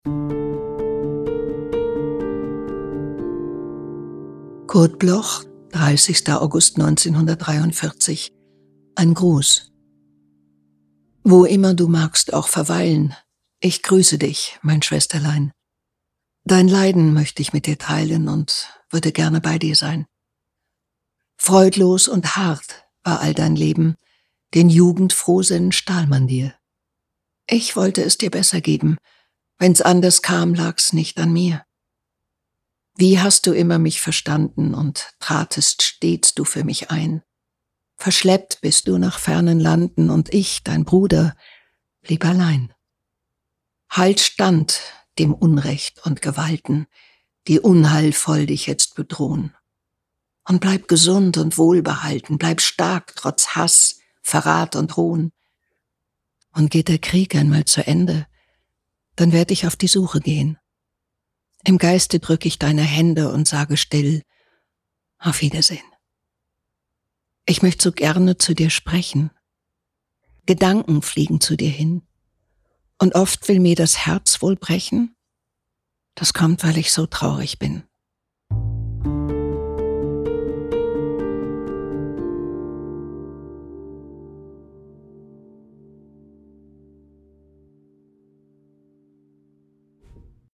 vorgetragen von Iris Berben
Iris-Berben_GRUSS_mit-Musik.m4a